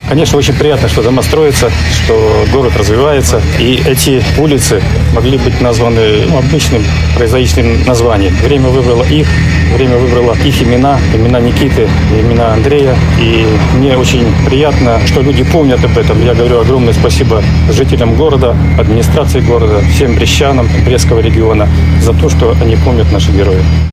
Сегодня в новом микрорайоне Северный-2 на улице майора Ничипорчика открыли мемориальную доску в честь погибших лётчиков, которая расположилась на доме №10.